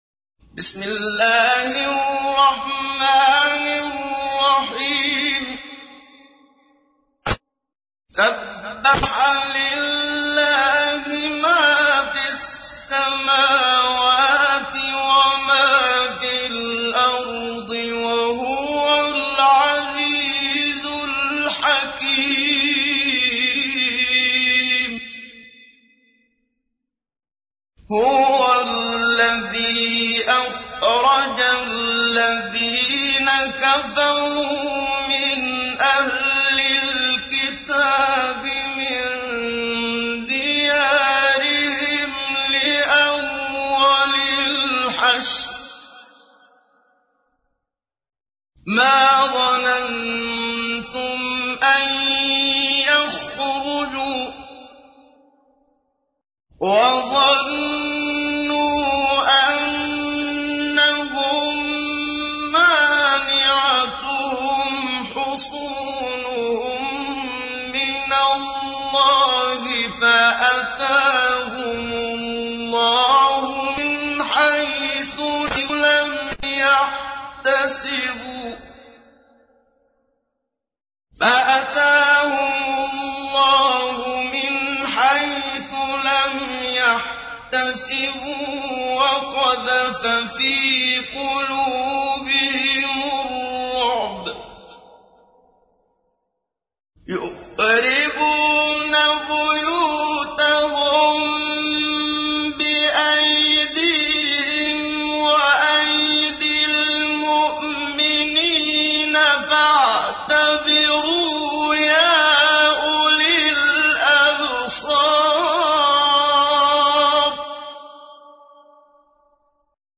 تحميل : 59. سورة الحشر / القارئ محمد صديق المنشاوي / القرآن الكريم / موقع يا حسين